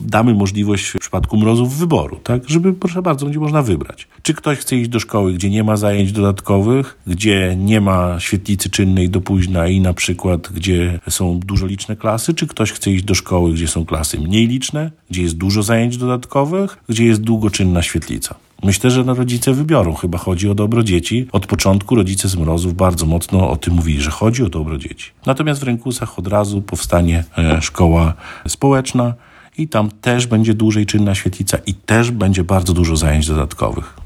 Jak informuje Tomasz Osewski, wójt gminy Ełk, od 1 września w Rękusach będzie funkcjonować już szkoła społeczna, natomiast w Mrozach Wielkich rodzice mają wybrać, do jakiej placówki poślą swoje pociechy.